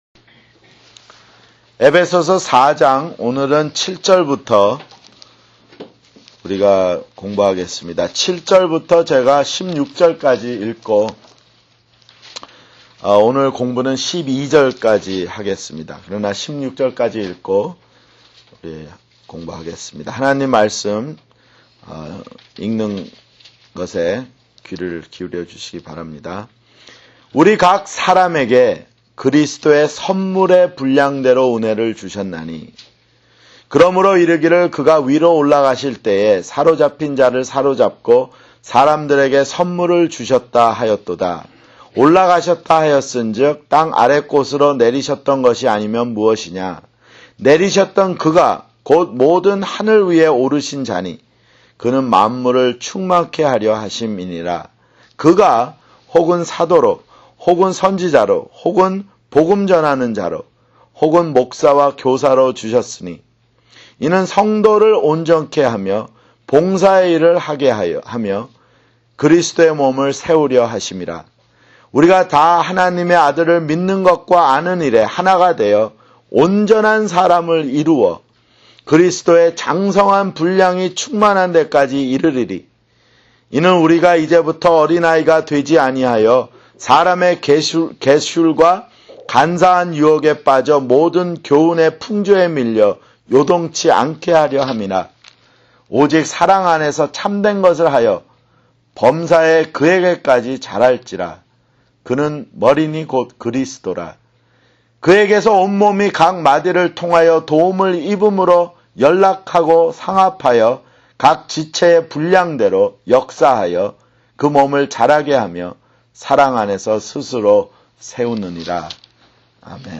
[성경공부] 에베소서 (49)